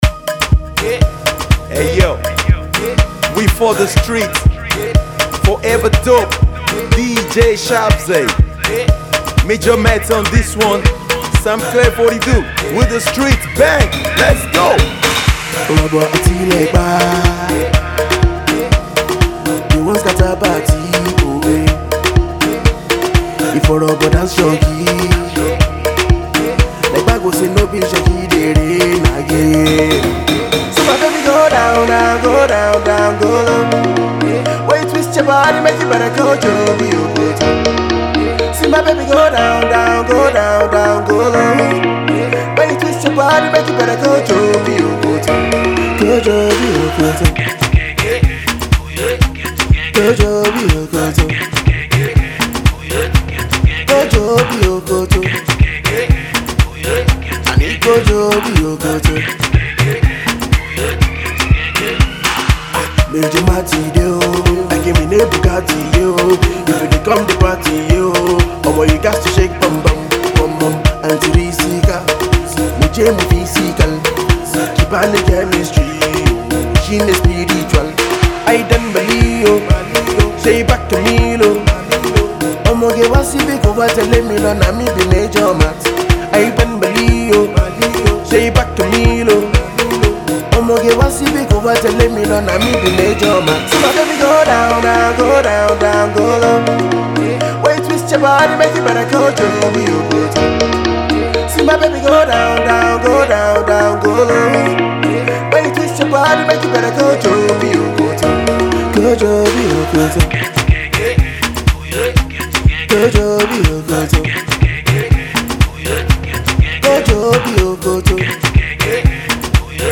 Alternative Pop
a good dance joint